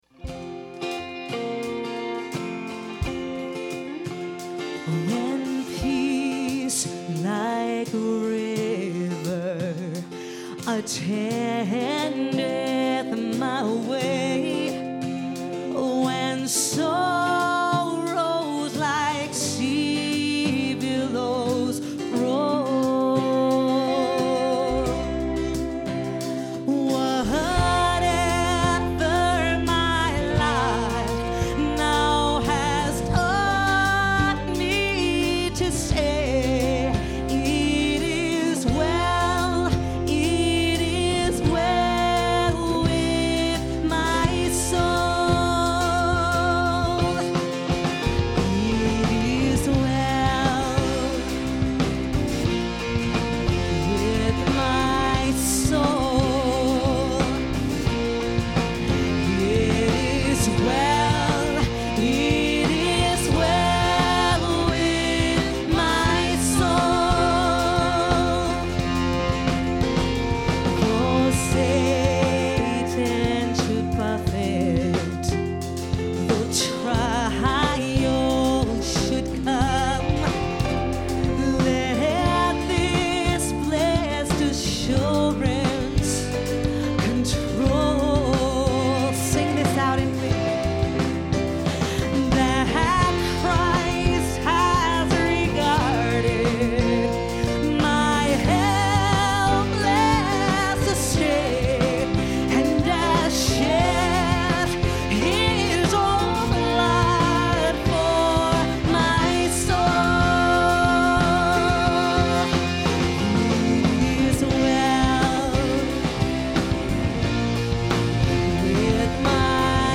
Wayfaring Stranger
"It Is Well" as played by Wayfaring Stranger at Terra Nova on 3.28.10.